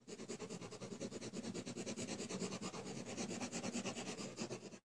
铅笔线
描述：铅笔
Tag: 拉伸 拉伸 线 铅笔